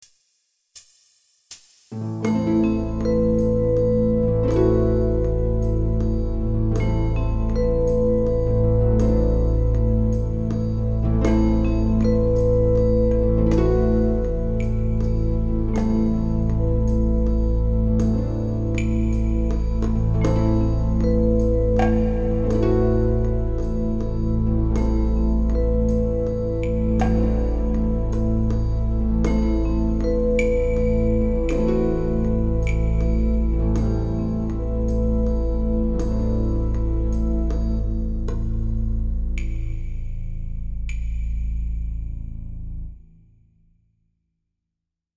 Tonalidade: la dórico; Compás: 3/4
acompanamento_dorico_2.mp3